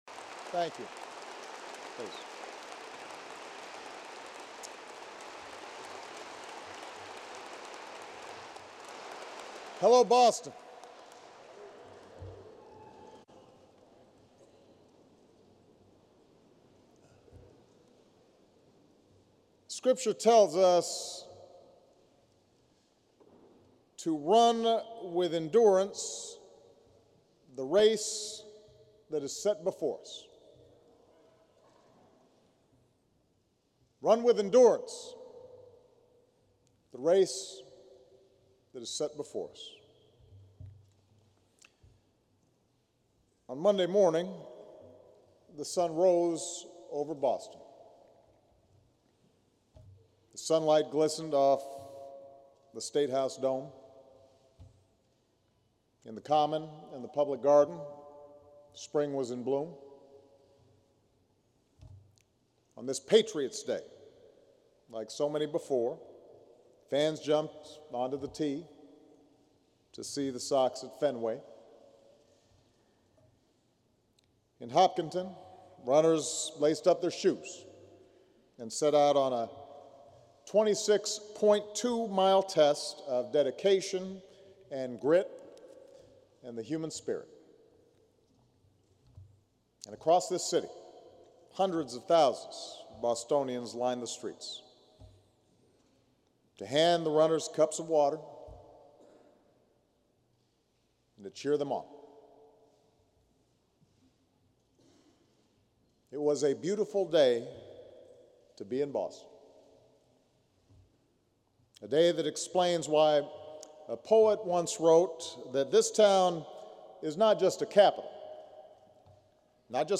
U.S. President Barack Obama delivers remarks at an interface service held at the Cathedral of the Holy Cross in Boston for the Boston Marathon bombing